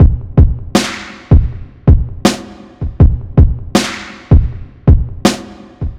Drum Loop.wav